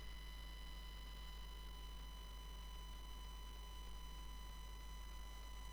Blue Snowball High Pitched Humming
My blue snowball which just came in the mail this morning is making a high pitched noise.
The noise is the 1KHz + harmonics whine which is generated [and radiated] by the computer …